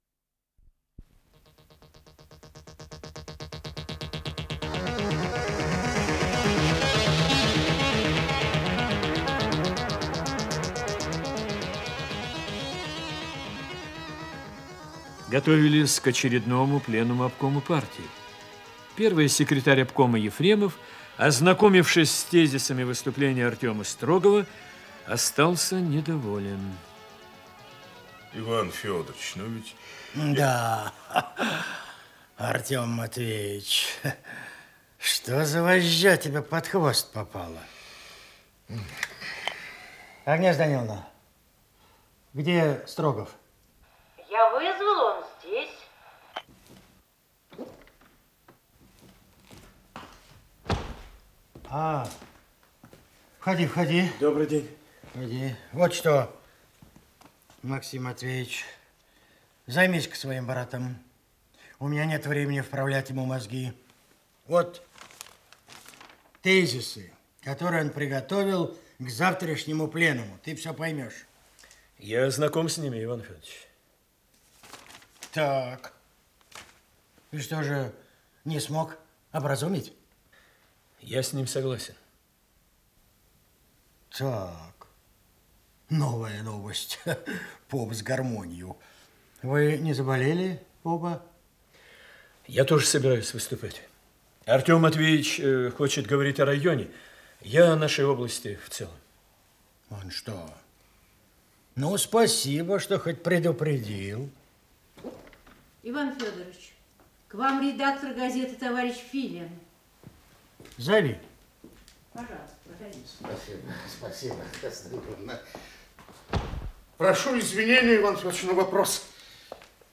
Исполнитель: Артисты Государственного академического Малого театра СССР
Радиокомпозиция спектакля